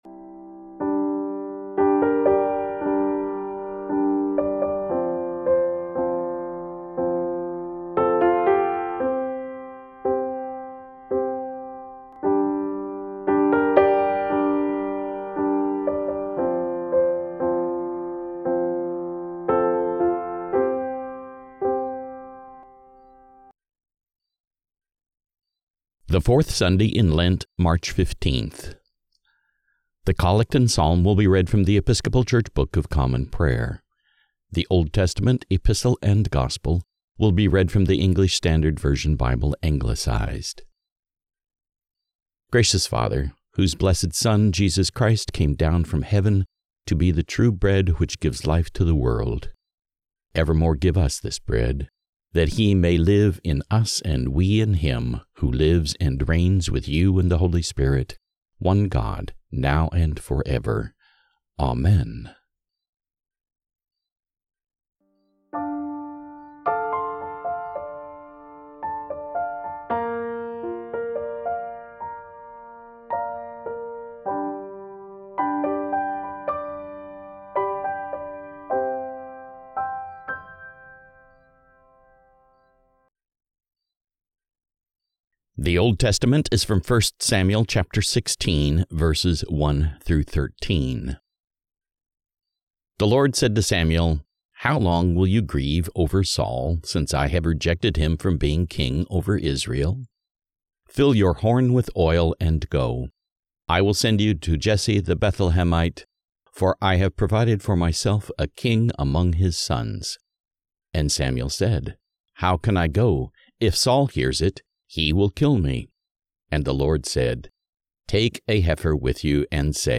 The Collect and Psalms will be read from The Episcopal Church Book of Common Prayer
The Old Testament, Epistle and Gospel will be read from the English Standard Version Bible Anglicized